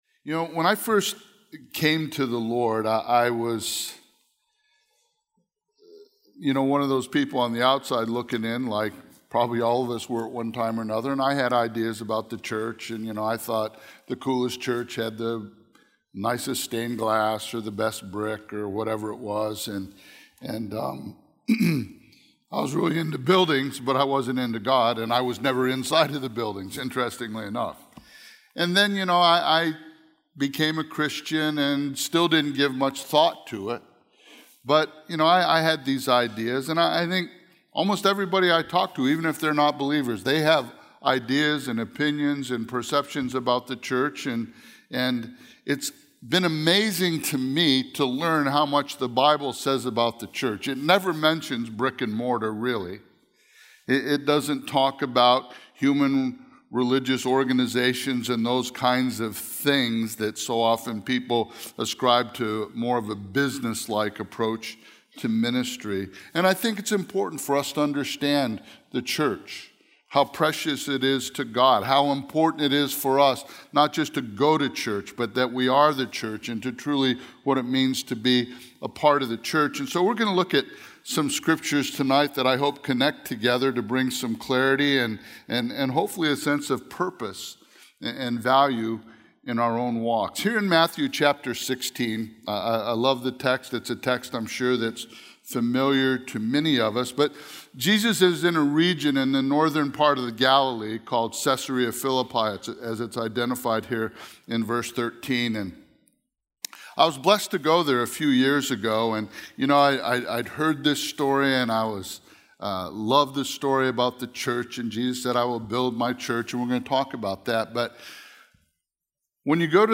A verse-by-verse expository sermon through Matthew 16:18